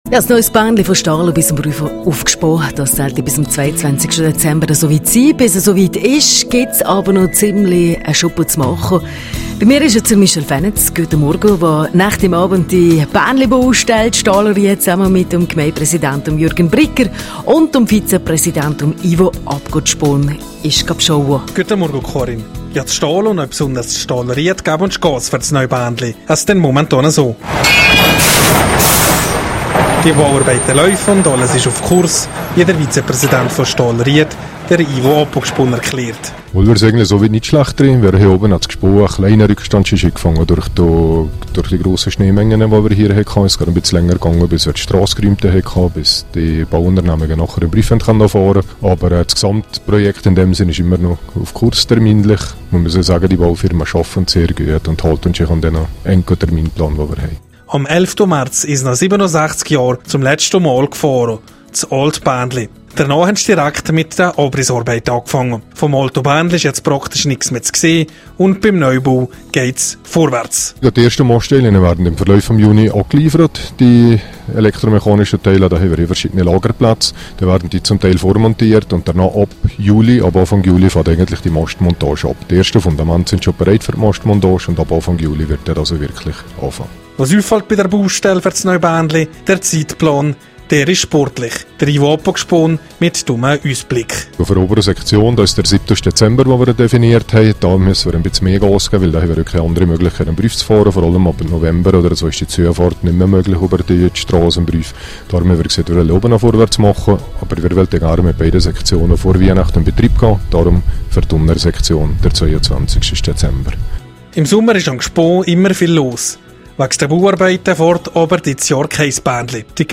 Jürgen Brigger, Gemeindepräsident von Staldenried, im Gespräch über den Neubau der Seilbahn Stalden-Staldenried-Gspon.